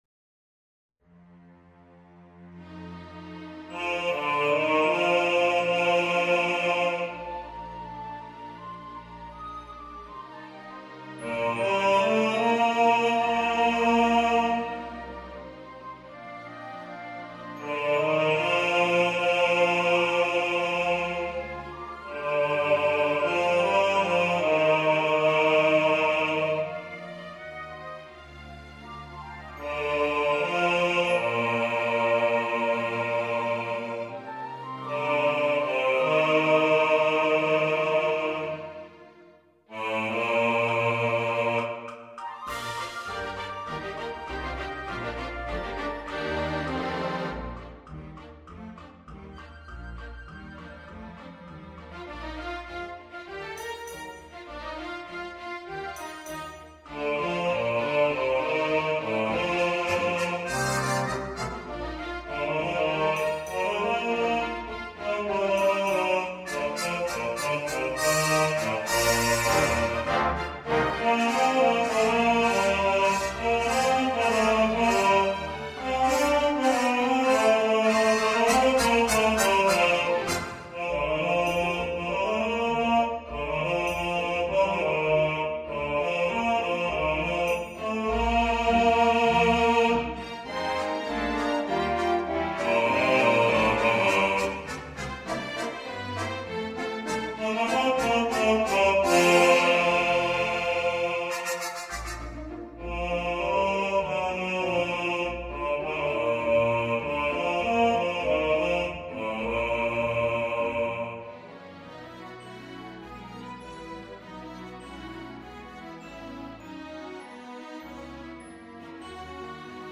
Santa Claus Is Coming To Town Bass | Ipswich Hospital Community Choir